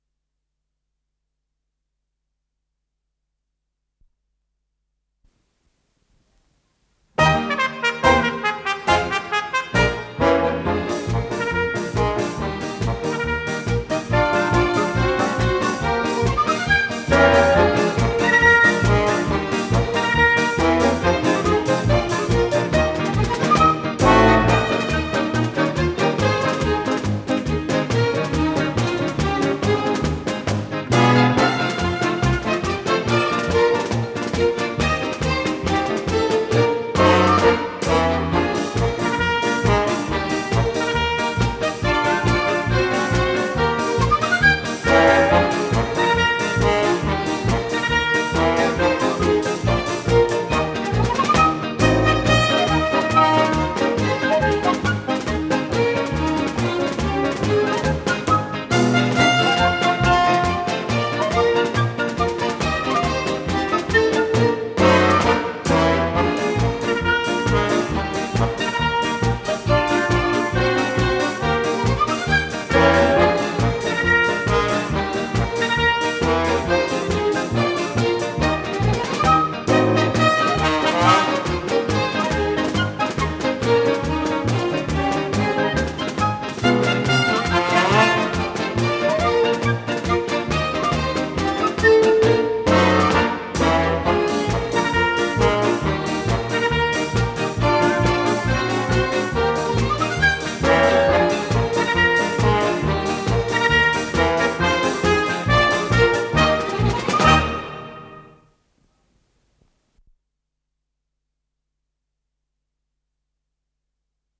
с профессиональной магнитной ленты
ПодзаголовокЗаставка, ми бемоль мажор
ВариантДубль моно